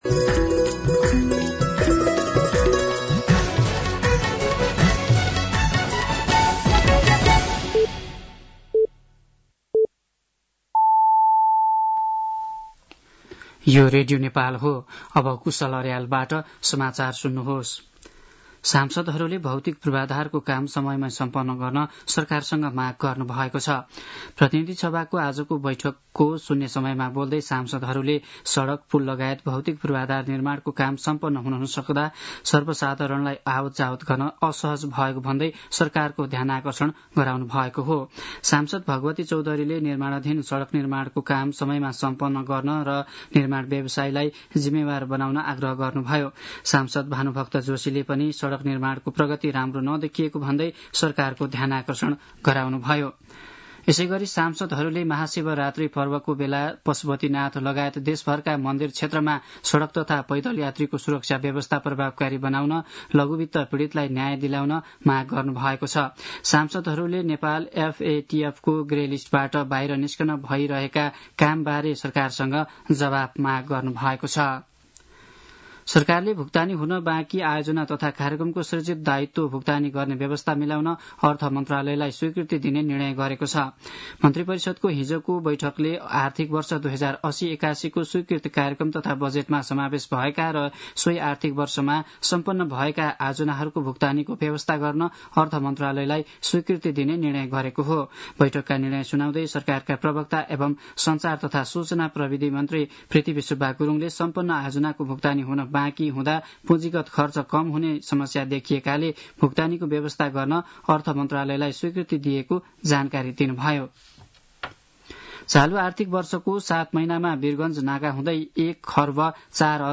साँझ ५ बजेको नेपाली समाचार : १४ फागुन , २०८१